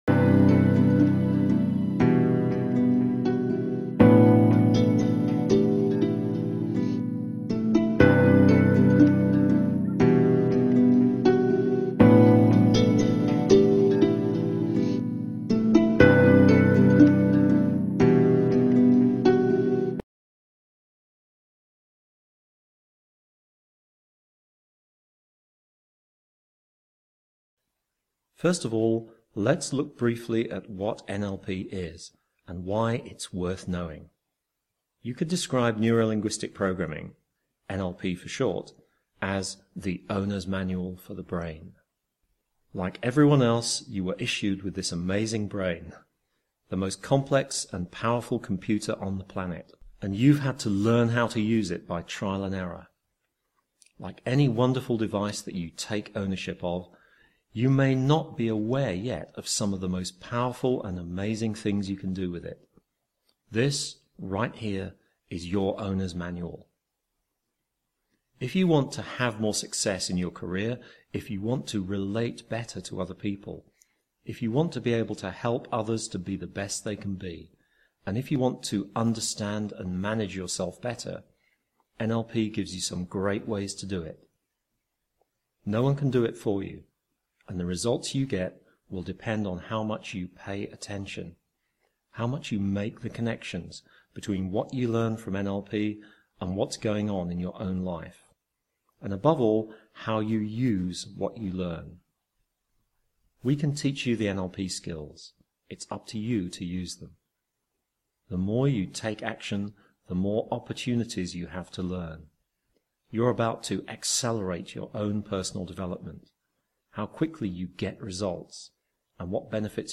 NLP-Lesson-1-Introduction-Cause-and-Effect.m4a